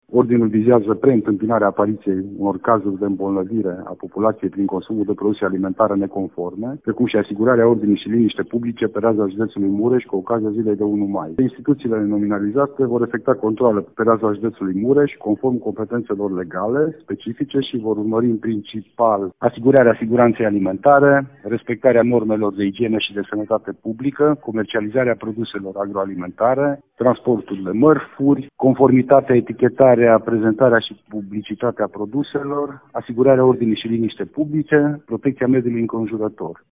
Verificările încep mâine și se derulează până în 3 mai, a precizat prefectul Lucian Goga: